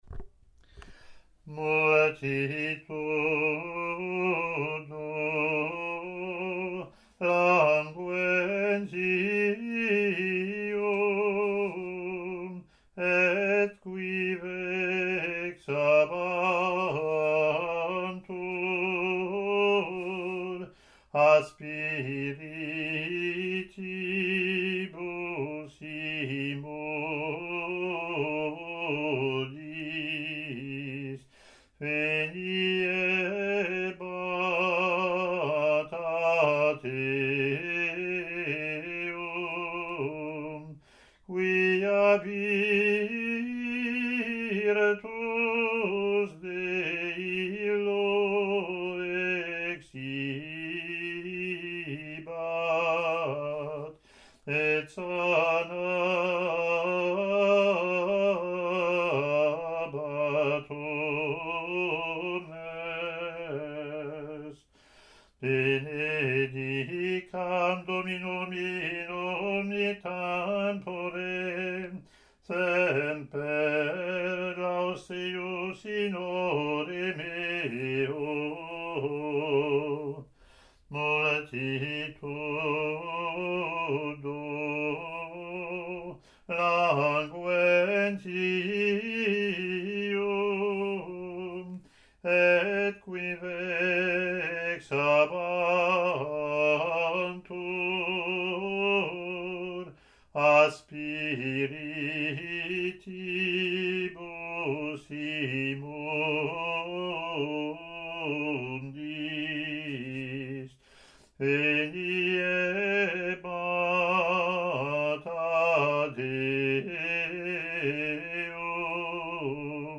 Year B Latin antiphon + verse)